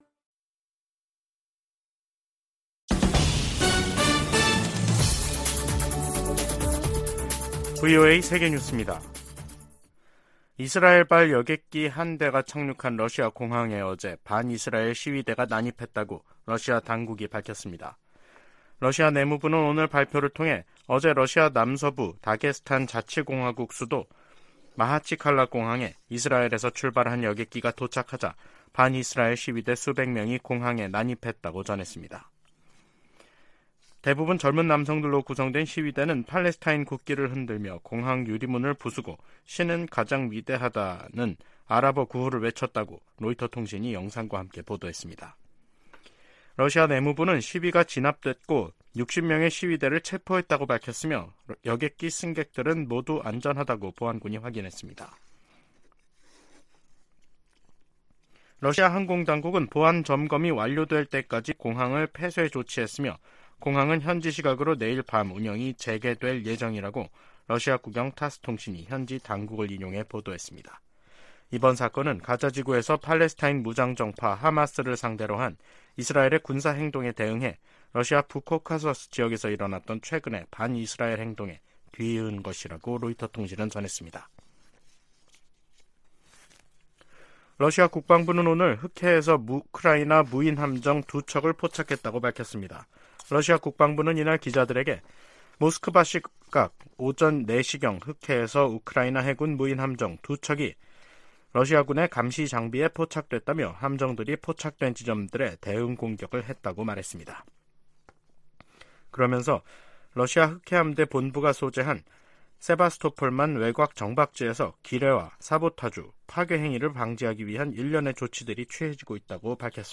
VOA 한국어 간판 뉴스 프로그램 '뉴스 투데이', 2023년 10월 30일 2부 방송입니다. 유엔총회 제1위원회가 북한의 핵무기와 대량살상무기 폐기를 촉구하는 내용이 포함된 결의안 30호를 통과시키고 본회의에 상정했습니다. 하마스가 이스라엘 공격에 북한제 대전차무기를 사용하고 있다고 중동문제 전문가가 말했습니다. 미 국무부는 북한과의 무기 거래를 부인한 러시아의 주장을 일축하고, 거래 사실을 계속 폭로할 것이라고 강조했습니다.